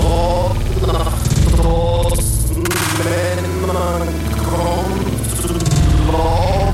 Ich hab' mal das Ding aus 0:28, 0:34 usw. verlangsamt. Ich höre da einen slide und keine einzelnen Töne. Der Eindruck entsteht vielleicht, weil am Ende des slides ein anderes Instrument eine Tonfolge abwärts spielt.